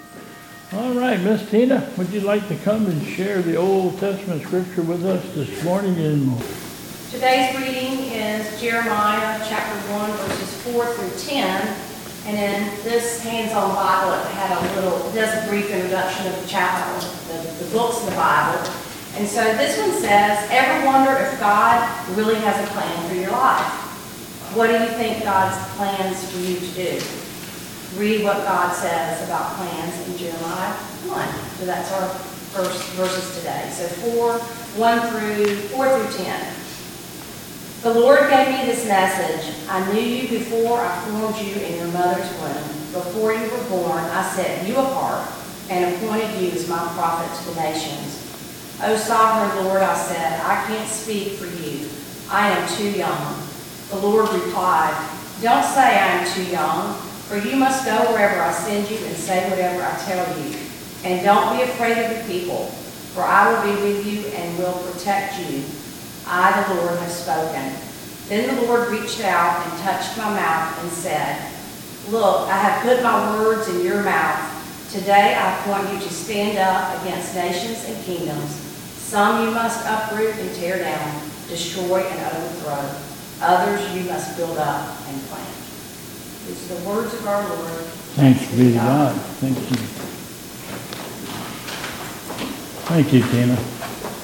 2022 Bethel Covid Time Service
Old Testament Reading